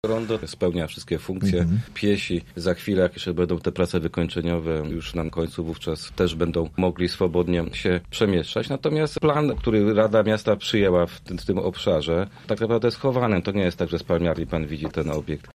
Wiceprezydent Zielonej Góry odniósł się także do wyglądu sklepu: https
Nasi goście rozmawiali w Politycznym Podsumowaniu Tygodnia.